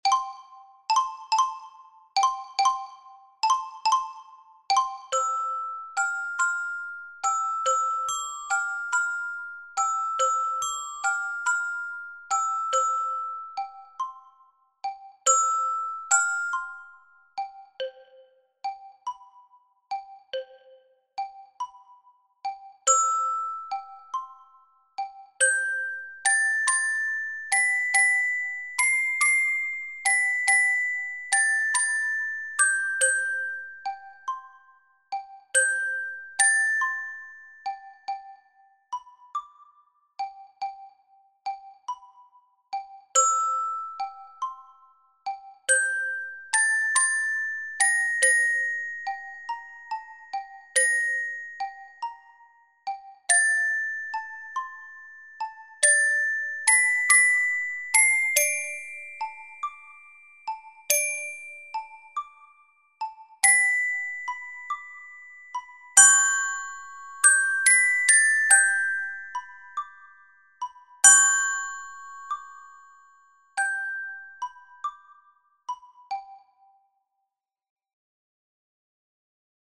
Here you have got the sound file with the silent bars.
The_Barcarolle_-_SILENCIOS.mp3